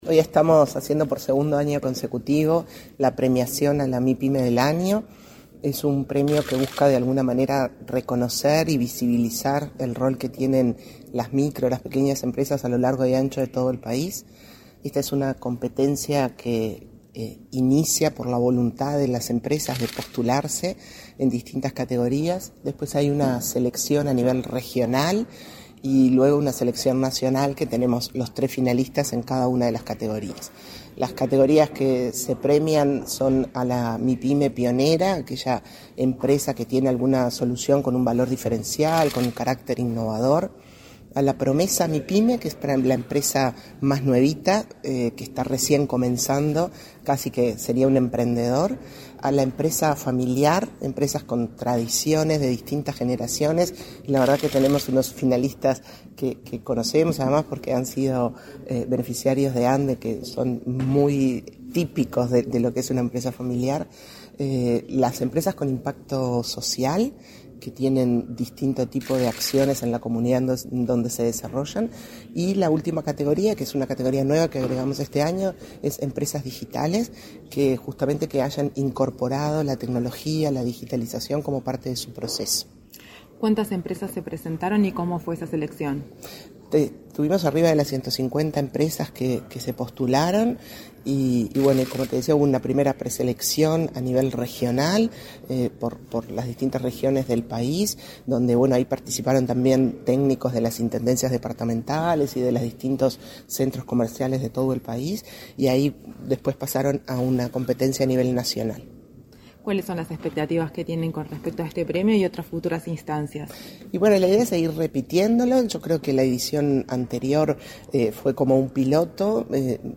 Entrevista a la presidenta de la ANDE, Cármen Sánchez
Entrevista a la presidenta de la ANDE, Cármen Sánchez 25/10/2022 Compartir Facebook X Copiar enlace WhatsApp LinkedIn Con la presencia de la vicepresidenta en ejercicio de la Presidencia de la República, Beatriz Argimón, se realizó este 25 de octubre la ceremonia del Premio MiPyme edición 2022. En el evento, la presidenta de la Agencia Nacional de Desarrollo (ANDE), Cármen Sánchez, dialogó con Comunicación Presidencial.